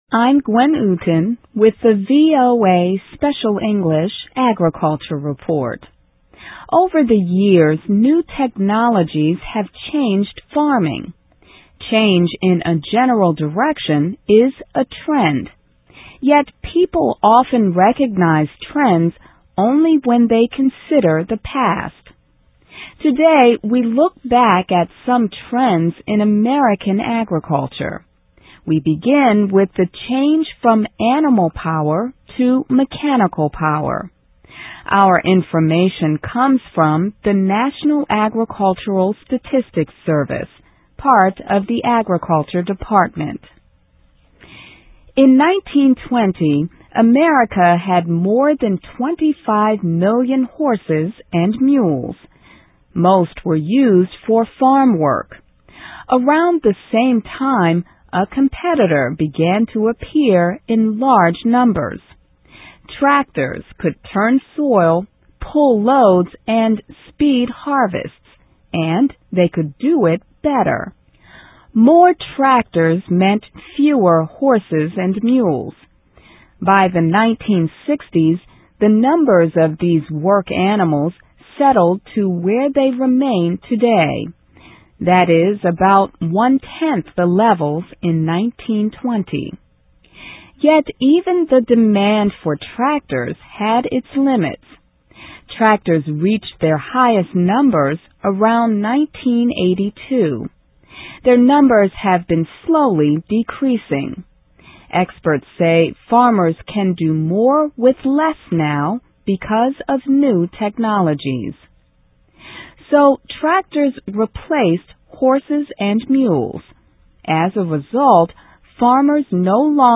American History: From Horses to Tractors, Changes in U.S. Agriculture (VOA Special English 2005-06-20)